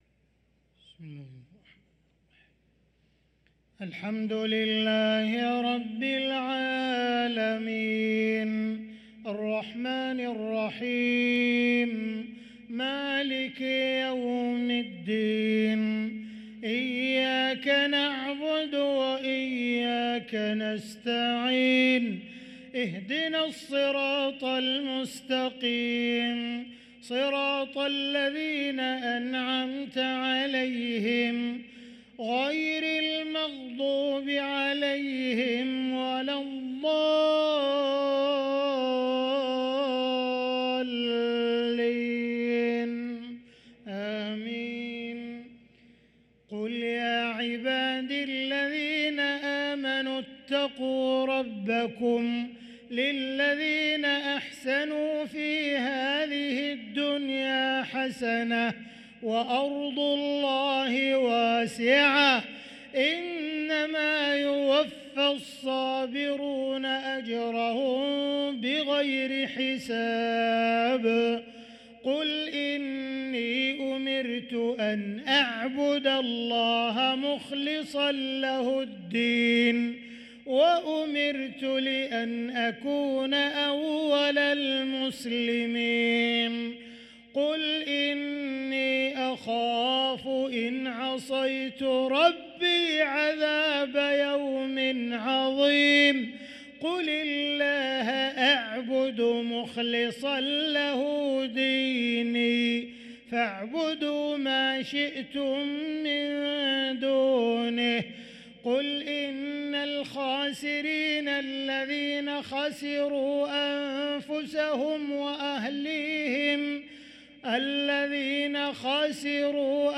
صلاة العشاء للقارئ عبدالرحمن السديس 9 صفر 1445 هـ
تِلَاوَات الْحَرَمَيْن .